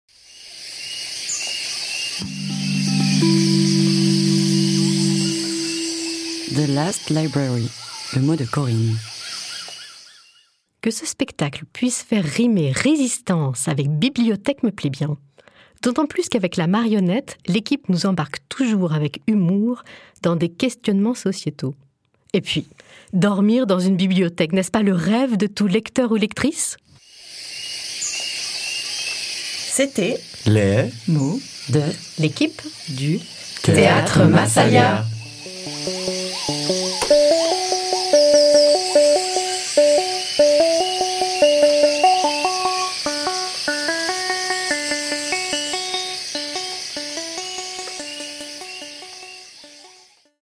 Le mot de l'équipe